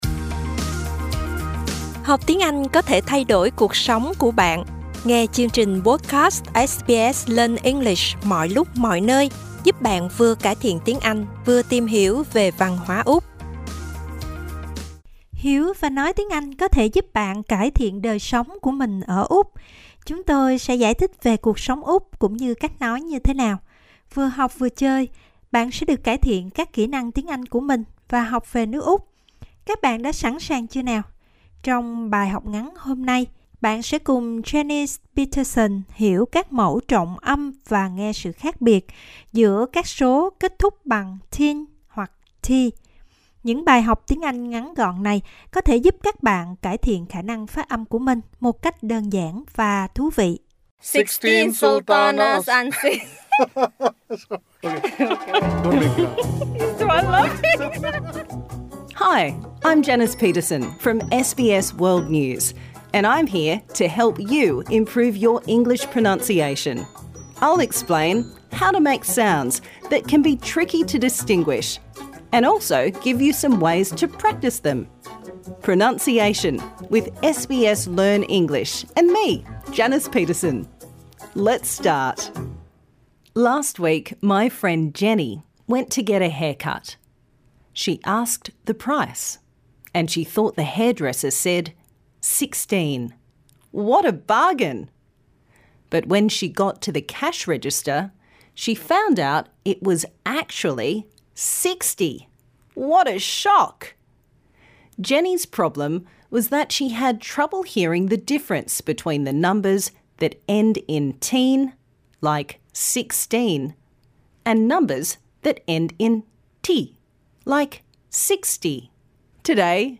Stress syllables
Text for Practise : I went to the store and bought thirteen tomatoes for thirty dollars, fourteen fish for forty dollars, fifteen forks for fifty dollars, etc. Minimal Pairs : ‘teen’ is the stressed syllable - it is long and clear and the /t/ is clearly pronounced: thirteen, fourteen...